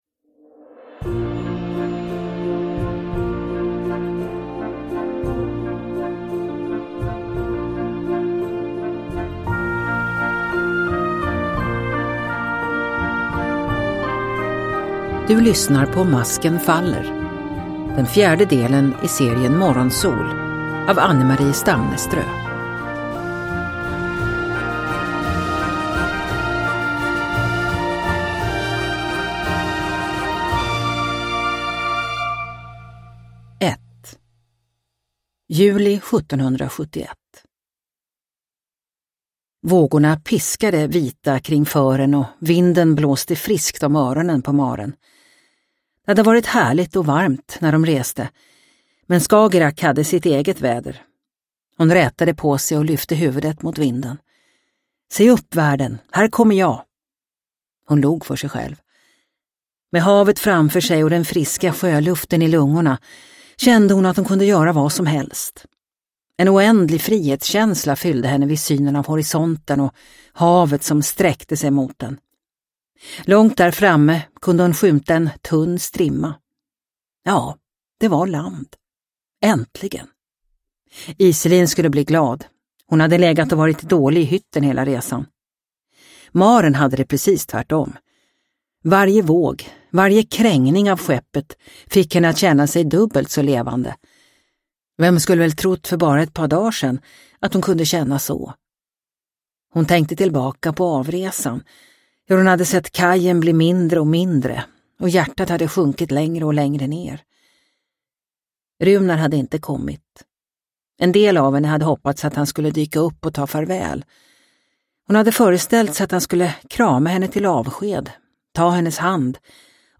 Masken faller – Ljudbok – Laddas ner